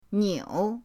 niu3.mp3